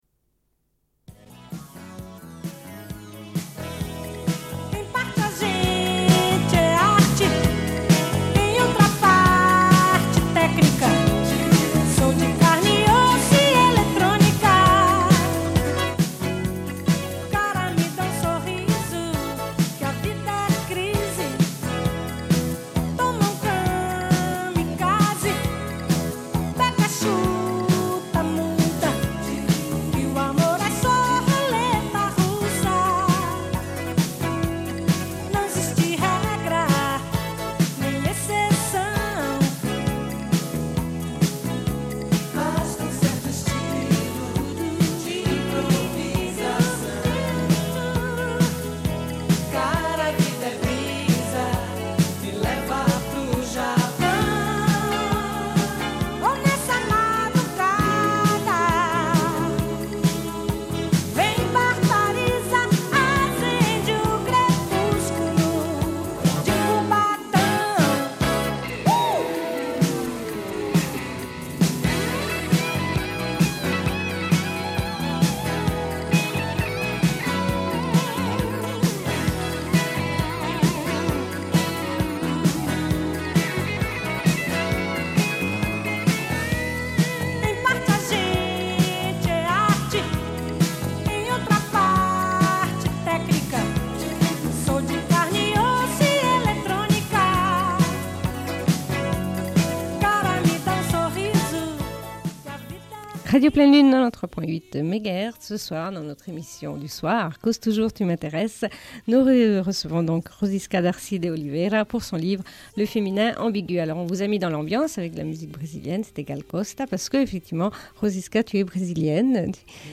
Une cassette audio, face A31:39
Émission Cause toujours tu m'intéresses avec Rosiska Darcy de Oliveira, sur son livre Le féminin ambigu.